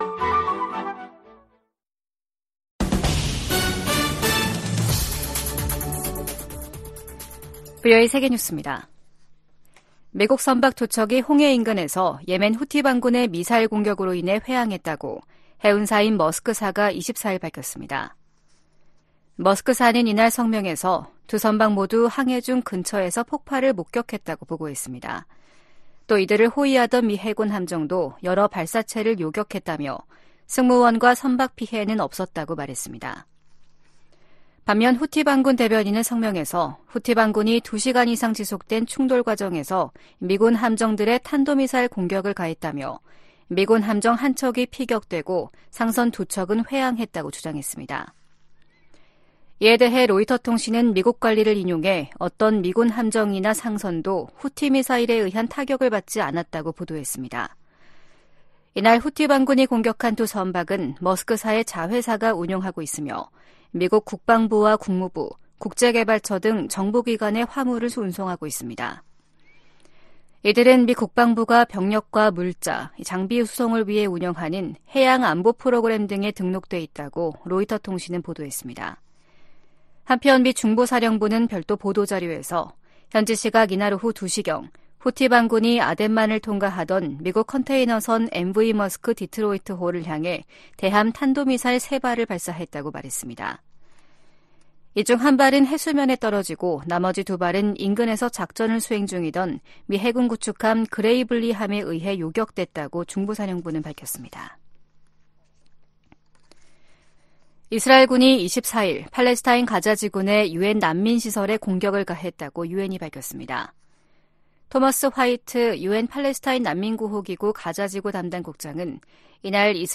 VOA 한국어 아침 뉴스 프로그램 '워싱턴 뉴스 광장' 2024년 1월 26일 방송입니다. 북한이 신형 전략순항미사일을 첫 시험발사했다고 밝혔습니다.